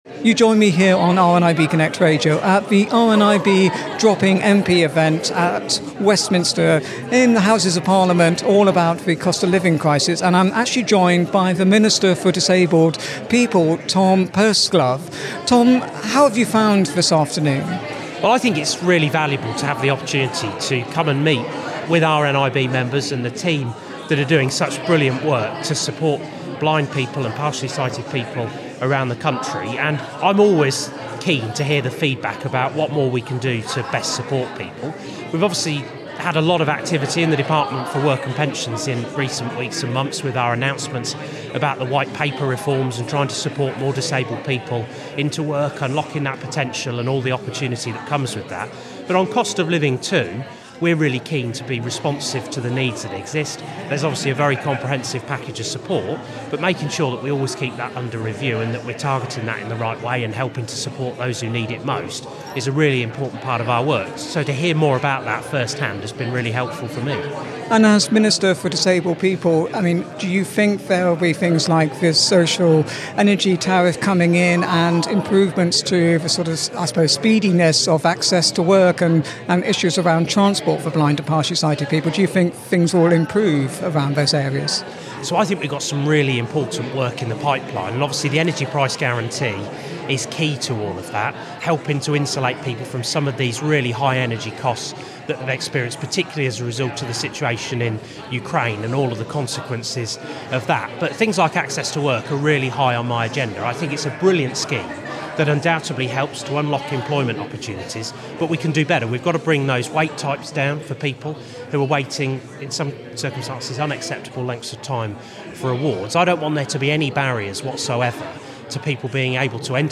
Tom Purseglove, Minister for Disabled People at the RNIB MP Drop-In Event
On Monday 24 April 2023 the RNIB held a MP drop-in event at the Houses of Parliament with an interactive game, a quiz and manned stalls to highlight and make MPs more aware of how the Cost of Living crisis is impacting on the lives of blind and partially sighted people.